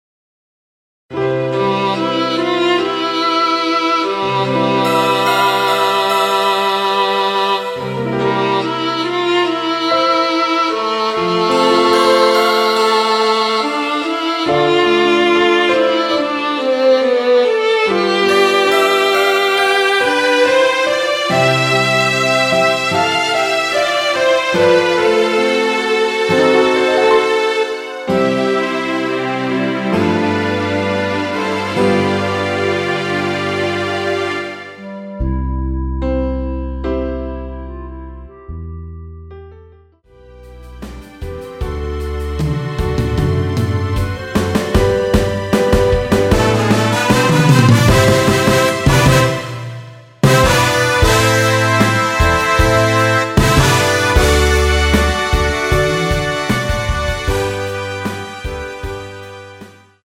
원키에서(-1)내린 멜로디 포함된 MR입니다.
노래방에서 노래를 부르실때 노래 부분에 가이드 멜로디가 따라 나와서
앞부분30초, 뒷부분30초씩 편집해서 올려 드리고 있습니다.